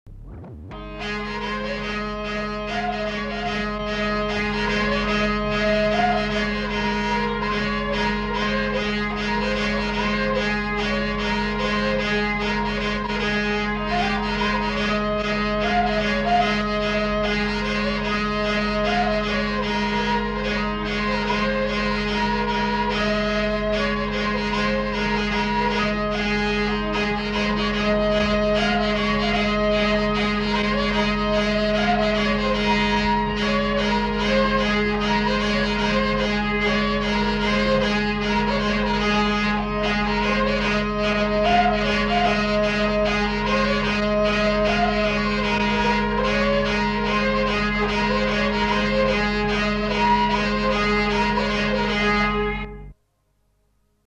Lieu : Herré
Genre : morceau instrumental
Instrument de musique : vielle à roue
Danse : scottish